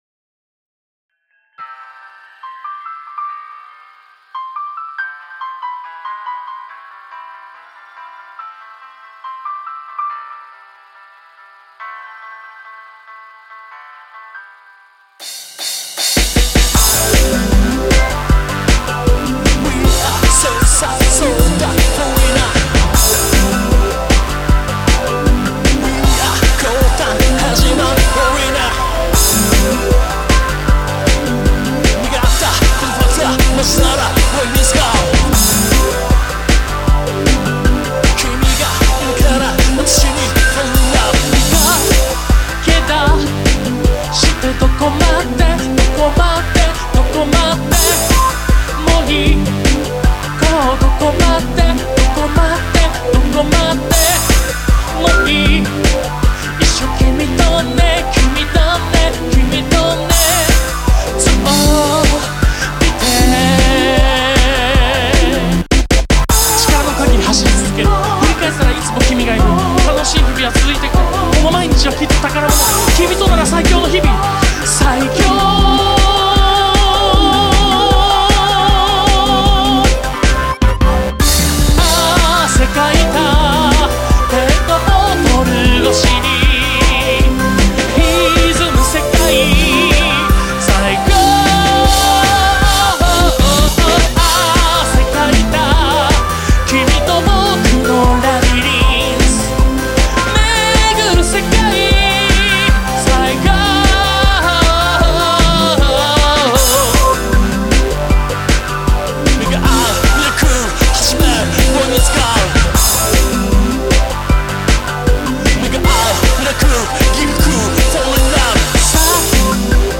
なおデモ音源になっているのは、去年、提供先のアイドルグループが立ち上がらなくて4曲ボツった内の一曲（仮歌は私）です。
作詞、作曲、アレンジ、仮歌のレコーディング、ミックスまで全部おっさんが一人でやりました。
ポップス